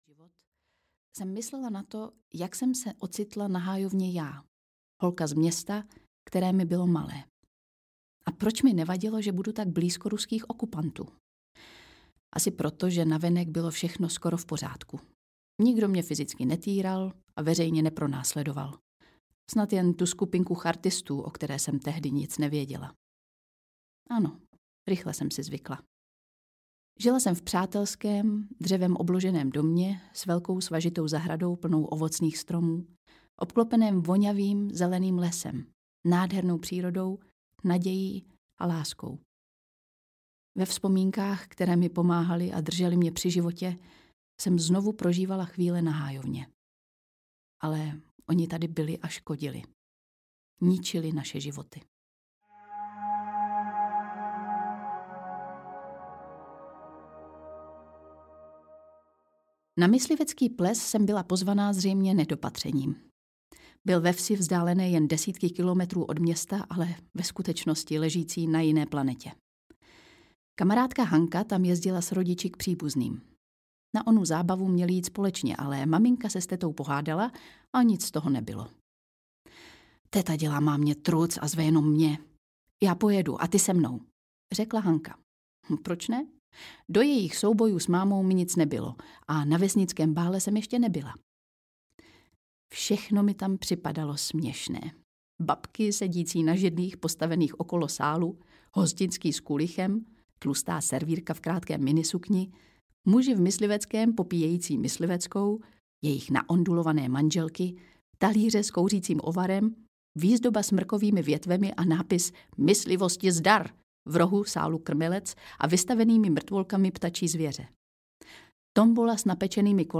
Hájovna audiokniha
Ukázka z knihy
• InterpretLucie Vondráčková